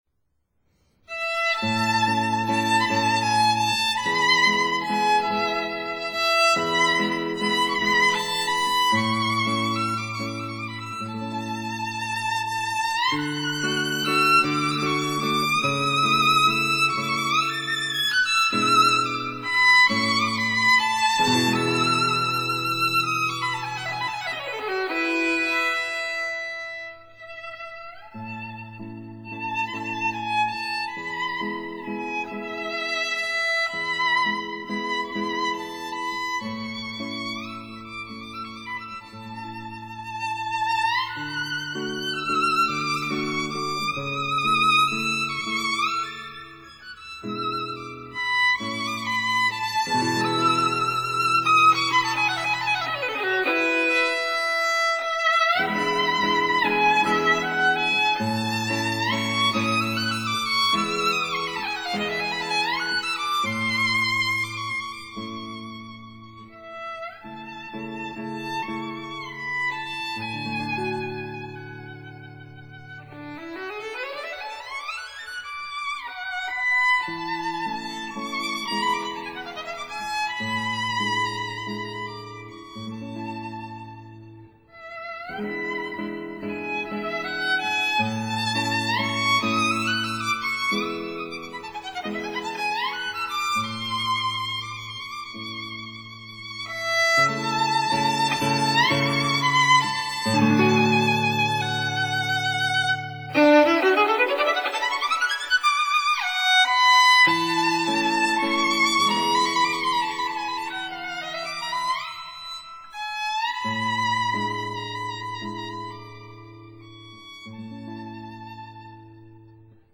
guitar Date